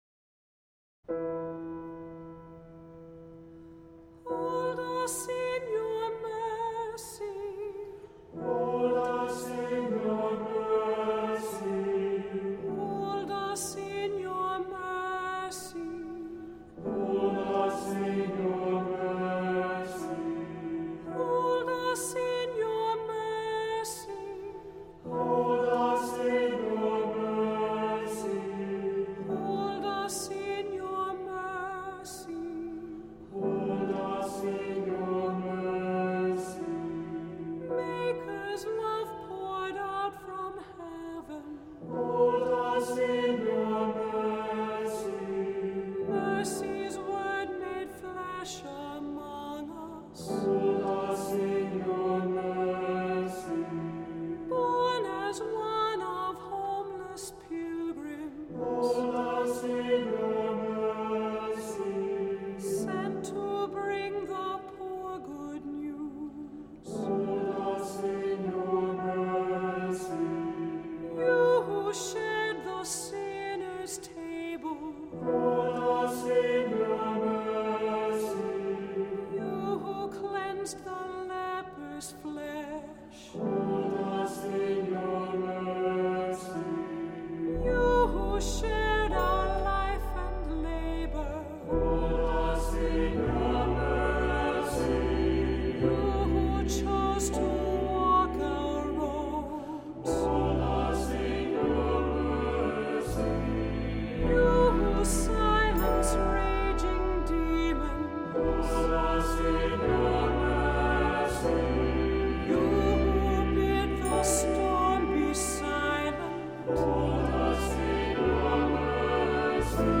Voicing: SATB; Cantor; Priest; Assembly